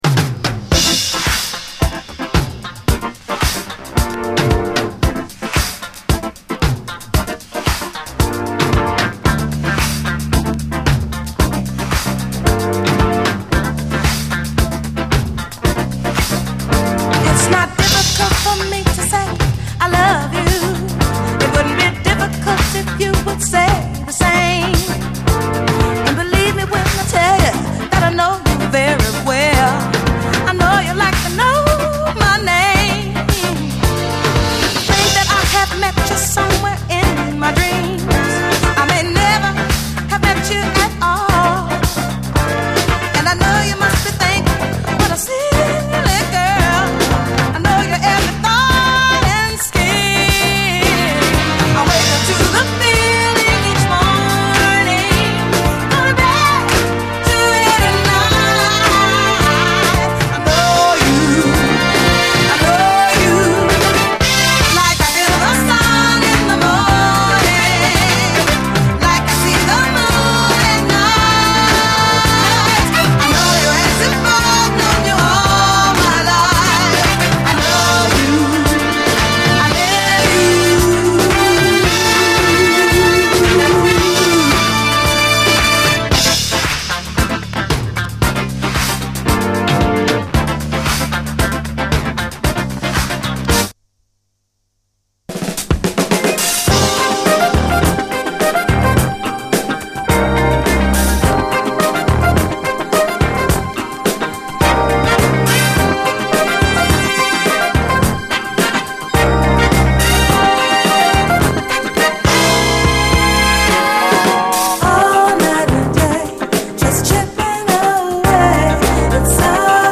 SOUL, 70's～ SOUL, DISCO
アーバンなメロウ・ダンス・クラシック